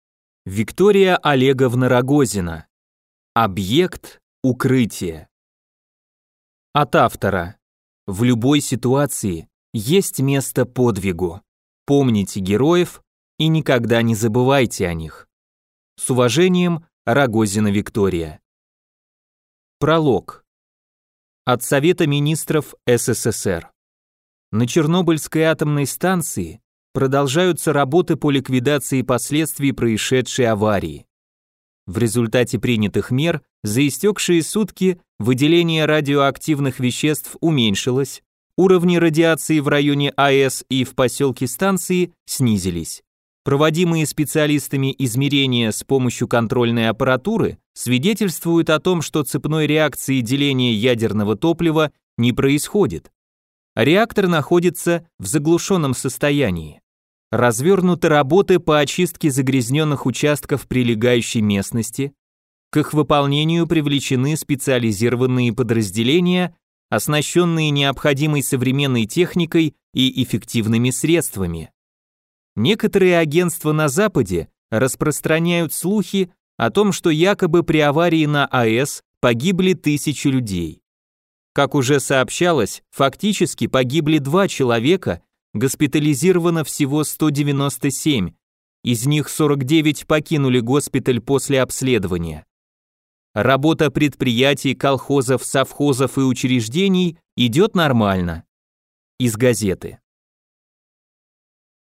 Аудиокнига Объект «Укрытие» | Библиотека аудиокниг